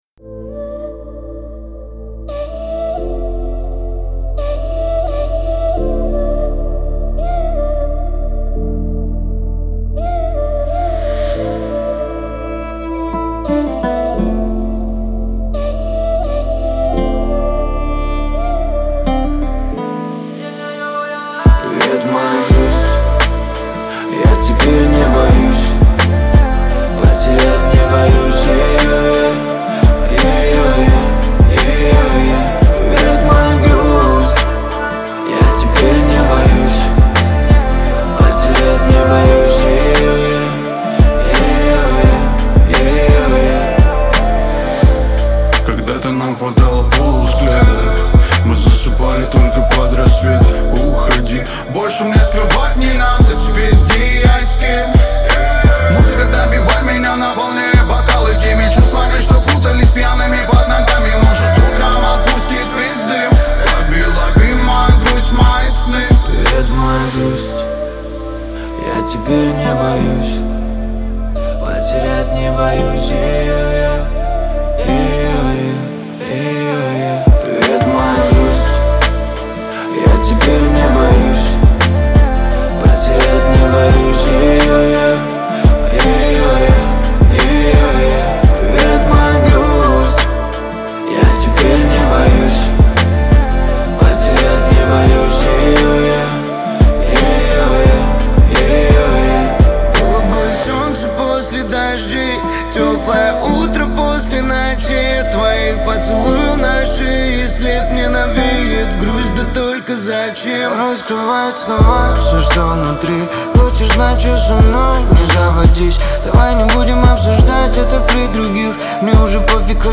это трек в жанре поп-музыки с элементами хип-хопа